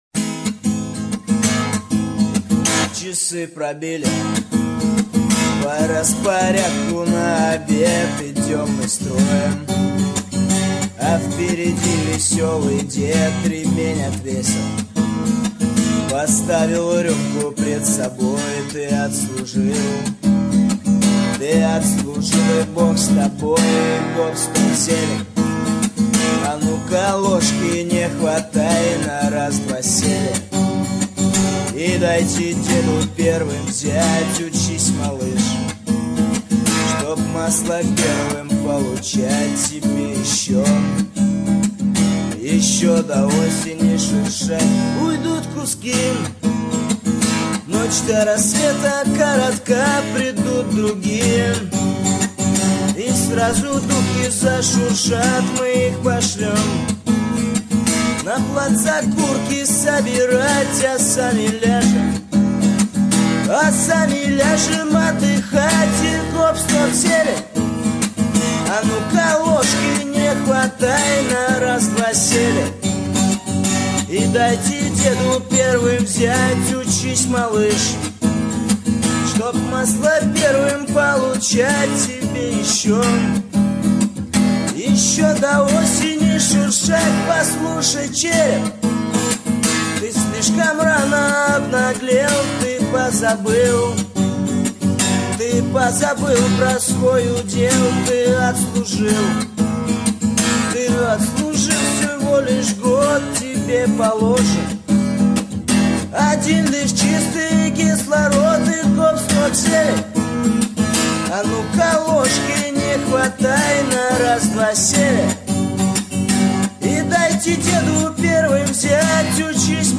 Армейские_песни_под_гитару_-_гоп-стоп_зелень